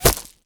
bullet_impact_gravel_04.wav